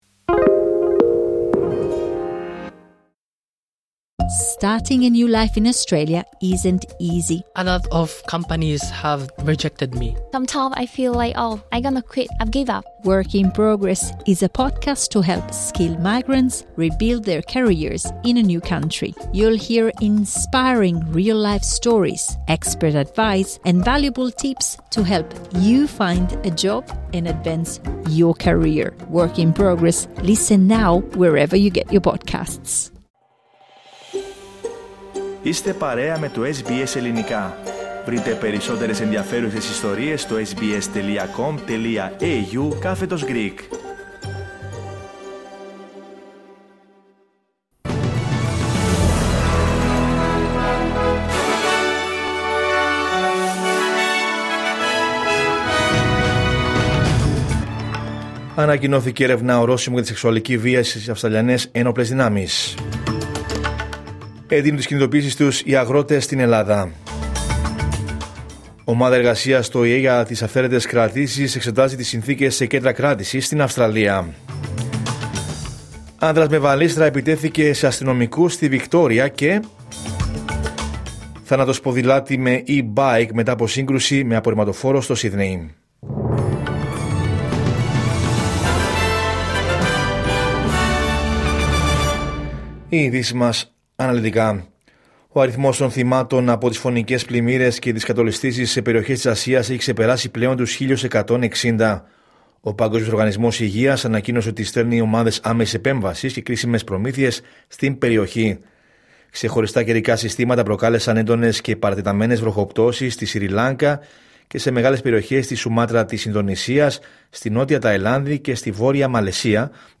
Ειδήσεις: Τρίτη 2 Δεκεμβρίου 2025